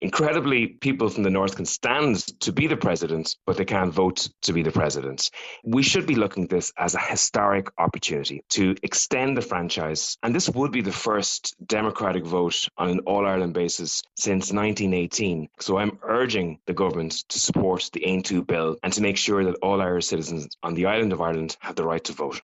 Over 600 thousand people in Northern Ireland claim Irish citizenship – party leader Peadar Tóibín says it’s only fair they should have a say: